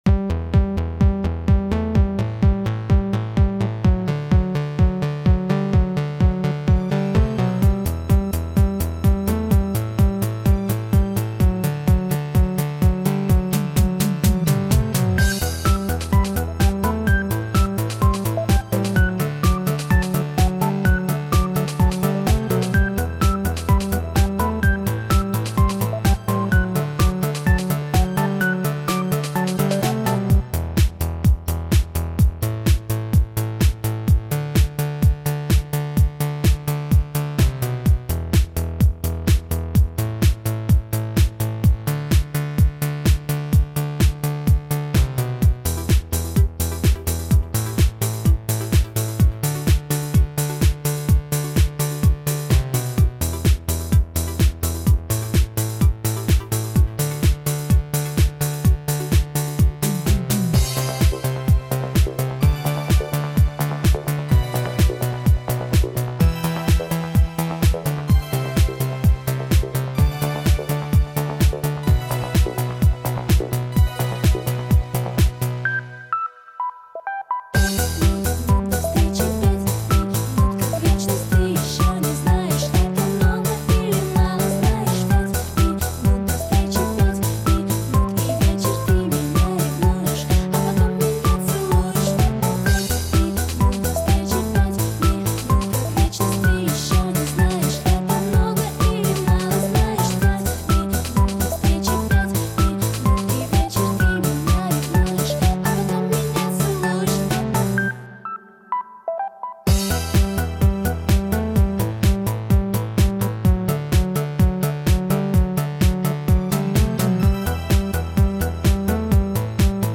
минусовка версия 92453